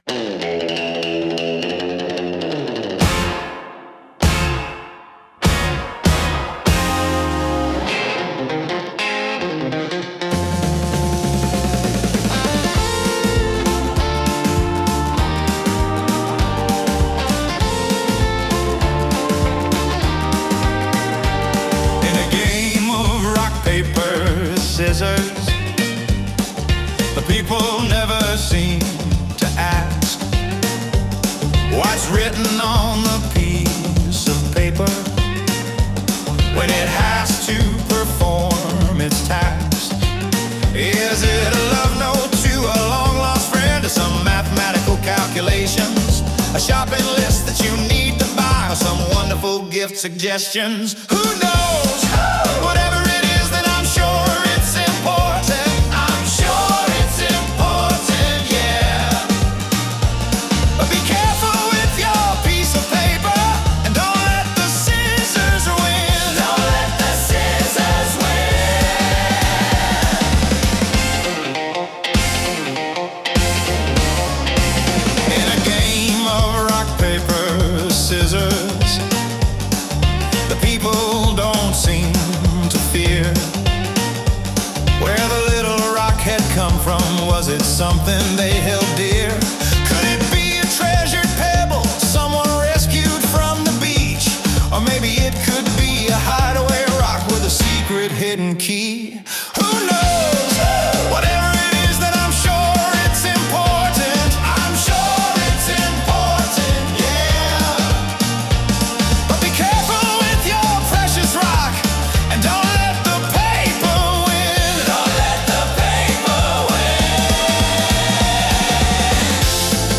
Sound Imported : Turbulent Robot
Sung by Suno